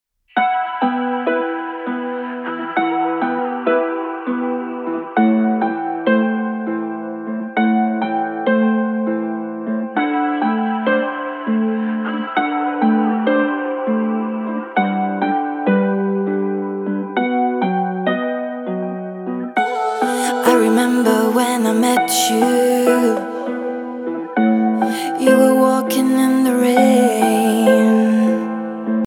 • Качество: 192, Stereo
поп
мелодичные
спокойные
красивый женский голос